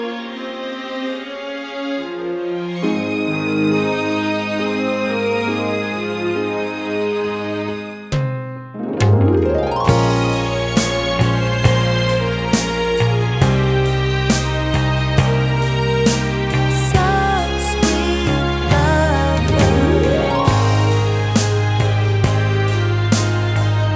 Country (Female)